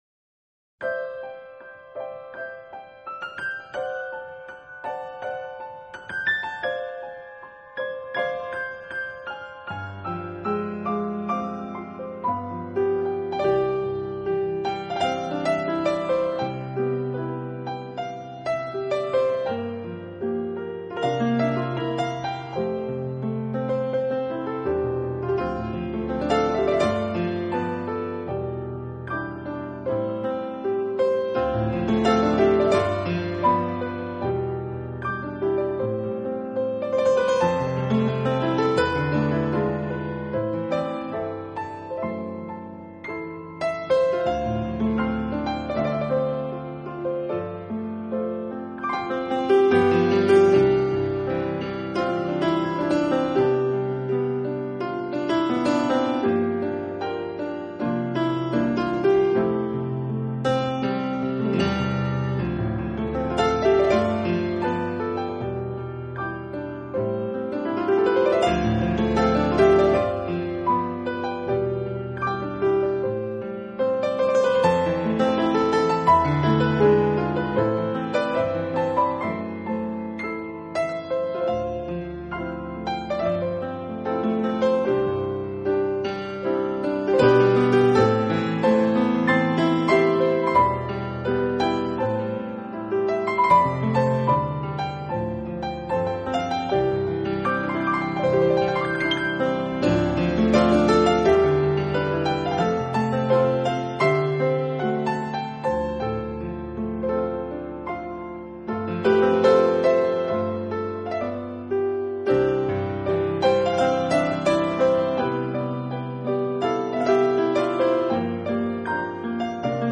鍵之間顯得雲淡風輕。
就鋼琴錄音，這張演奏專輯是必備的選擇之一，琴音清脆滑潤，大珠小珠落玉盤，發
燒級的透明可人。